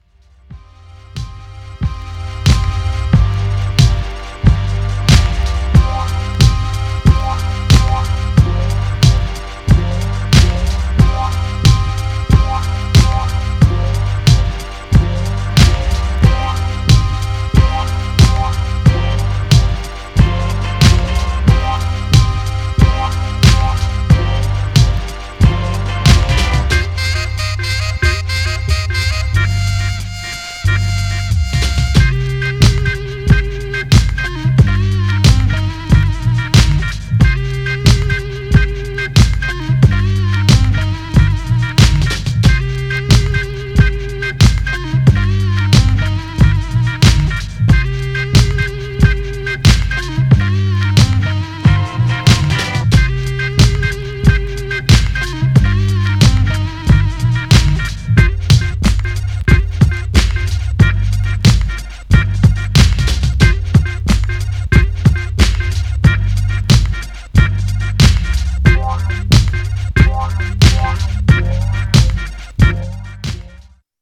Styl: Hip Hop, Lounge